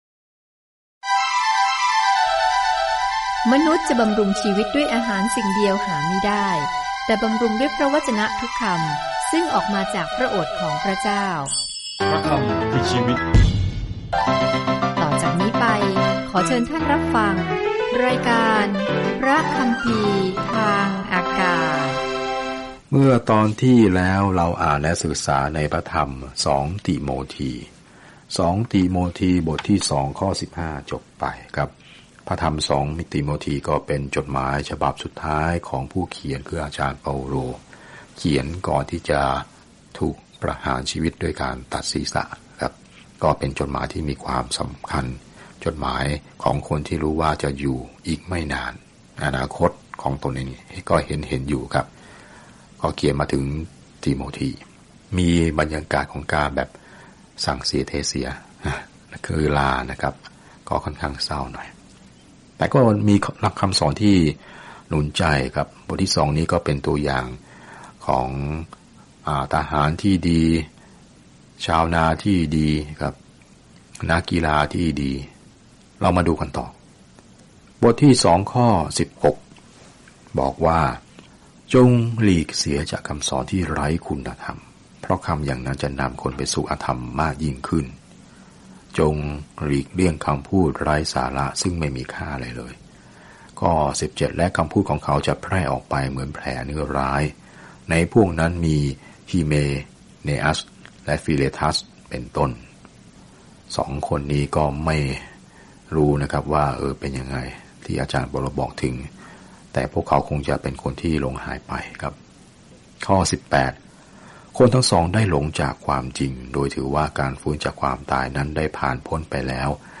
จดหมายฉบับที่สองถึงทิโมธีเรียกร้องให้คนของพระเจ้ายืนหยัดต่อพระวจนะของพระเจ้า ปกป้องพระวจนะ สั่งสอน และหากจำเป็น จงทนทุกข์เพื่อพระวจนะนั้น เดินทางทุกวันผ่าน 2 ทิโมธีในขณะที่คุณฟังการศึกษาด้วยเสียงและอ่านข้อที่เลือกจากพระวจนะของพระเจ้า